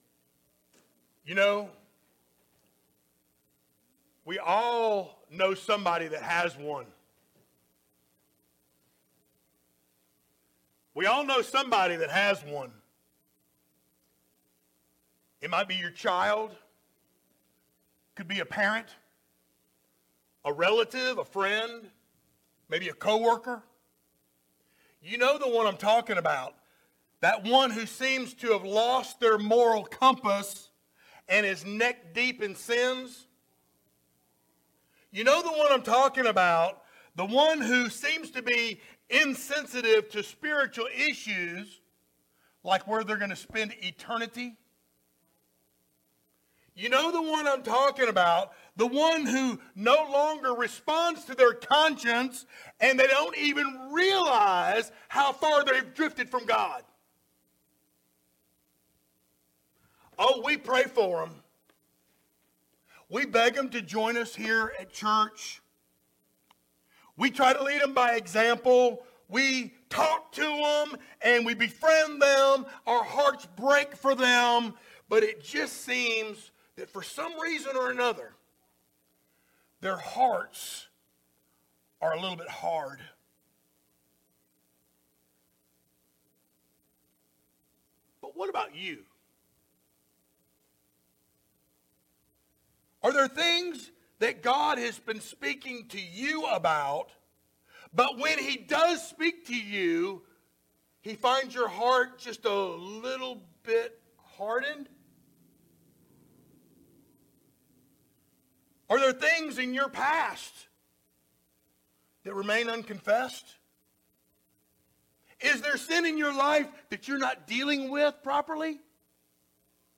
Series: sermons
Genesis 42:1-28 Service Type: Sunday Morning Download Files Notes Topics